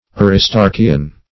Aristarchian \Ar`is*tar"chi*an\, a.